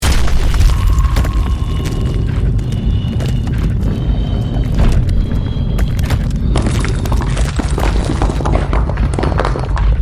Звук коллапса каменной аномалии